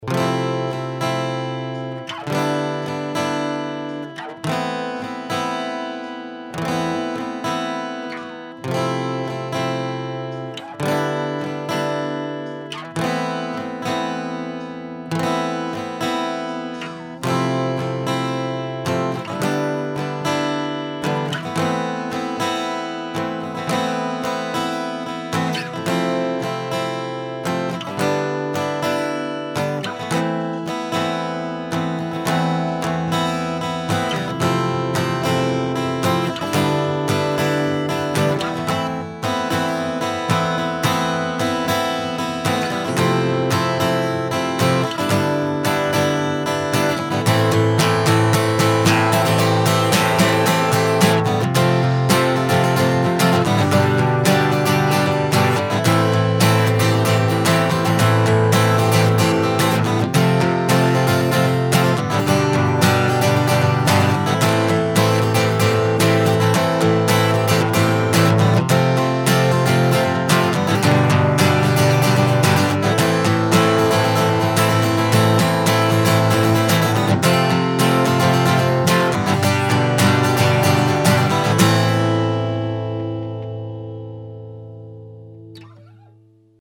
- отличный звук в миксе как при подключении просто в линию, так и при съеме микрофоном (у товарищей тейлоры начальных серий 1хх звучат просто хуже, а в микс вообще не ложатся  :7: ):
запись происходила так: в одном канале пьезо (родной китайский ФилПрошный датчик с родным преампом), а в другом съем микрофоном (шур см58), струны 13-60 дадарио давностью больше года  :crazy: